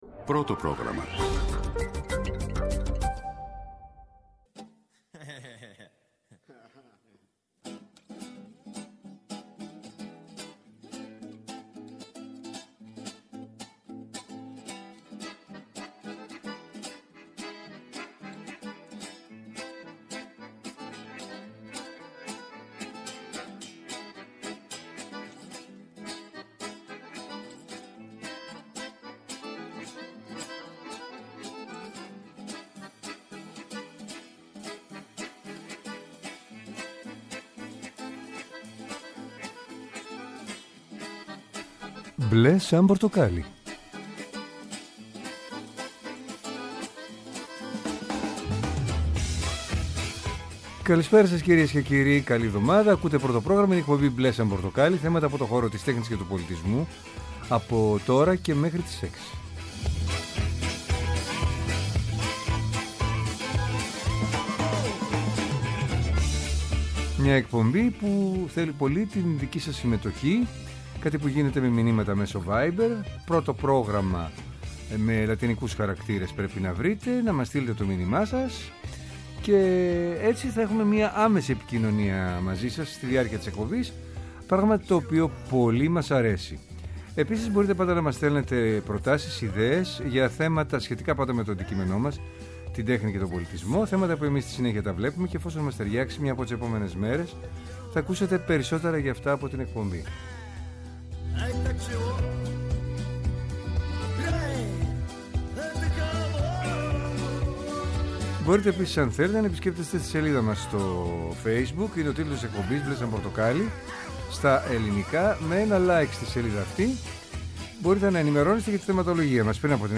Θέατρο, κινηματογράφος, μουσική, χορός, εικαστικά, βιβλίο, κόμικς, αρχαιολογία, φιλοσοφία, αισθητική και ό,τι άλλο μπορεί να είναι τέχνη και πολιτισμός, καθημερινά από Δευτέρα έως Παρασκευή 5-6 το απόγευμα από το Πρώτο Πρόγραμμα. Μια εκπομπή με εκλεκτούς καλεσμένους, άποψη και επαφή με την επικαιρότητα.